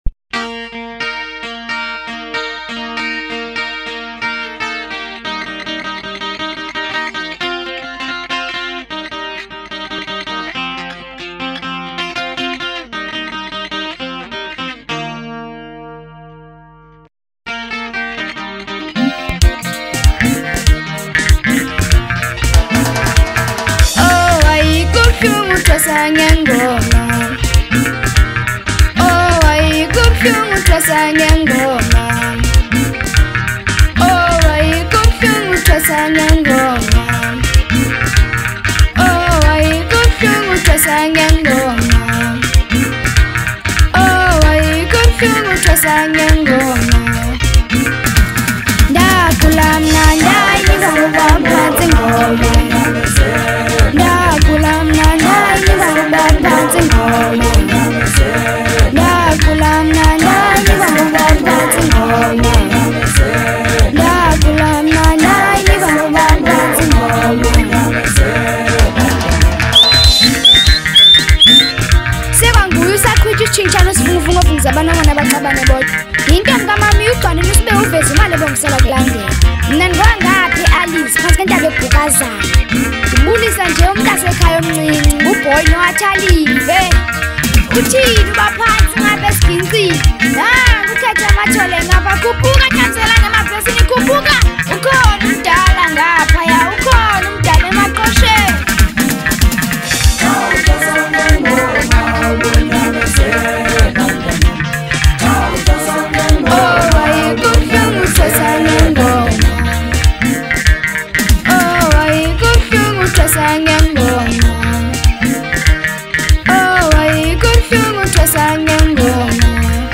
04:08 Genre : Maskandi Size